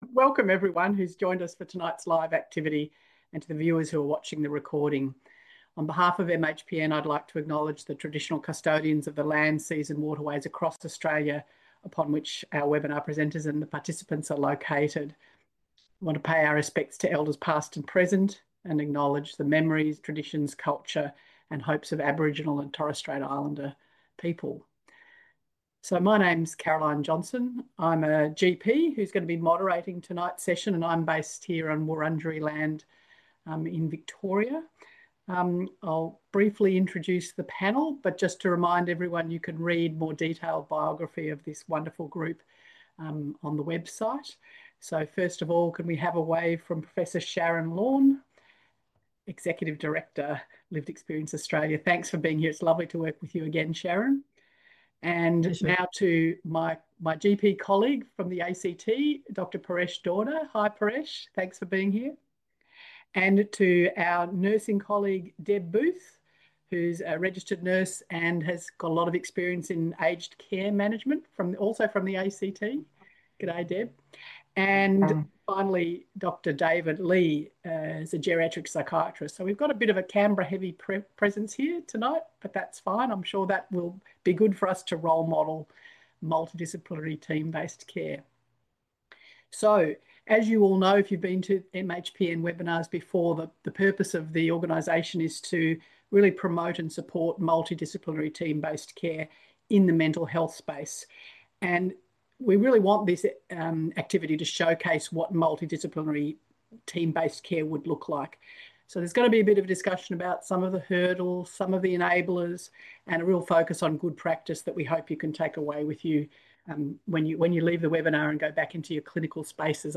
At this webinar, our panel explore how multidisciplinary teams can play a key role in supporting older people to live independently in the community. Thy discuss the complexities that often come with ageing and explore how collaborative care can respond to a range of physical, mental, and social needs.